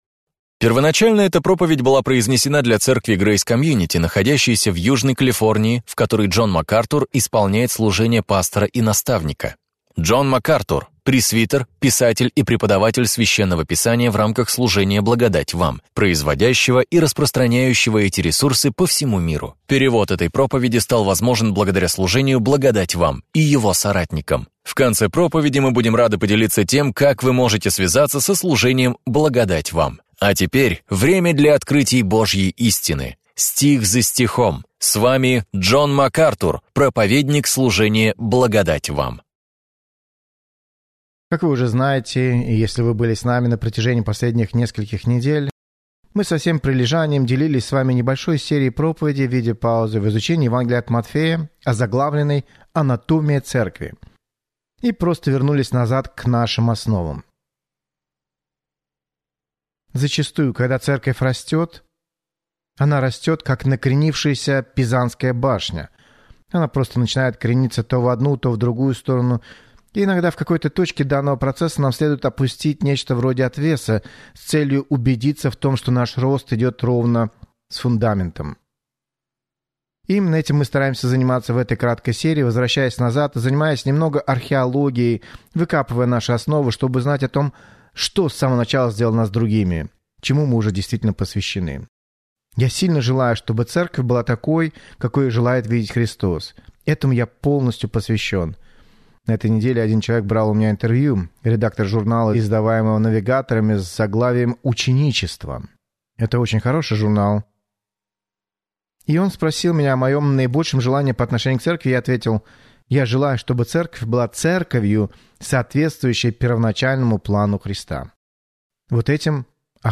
«Анатомия Церкви» – это ценная проповедь, поясняющая, как вы и ваша церковь можете прославлять Бога!